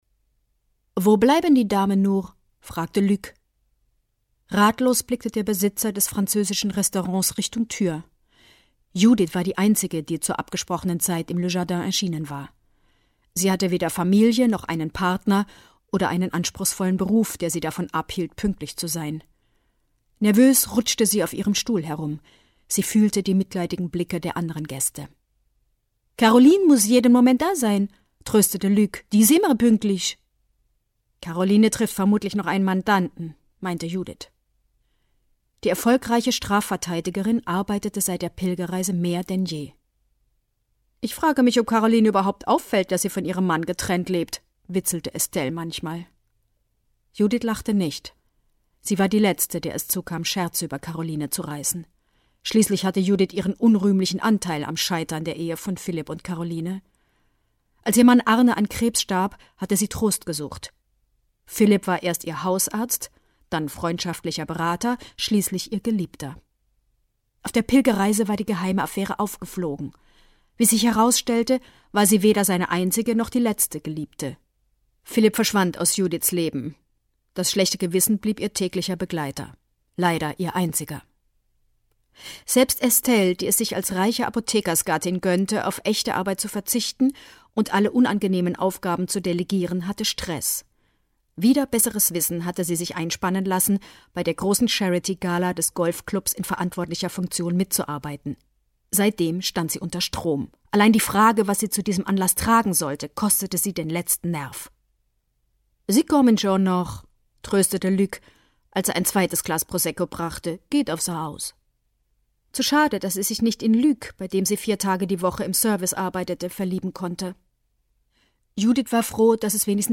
Sieben Tage ohne - Monika Peetz - Hörbuch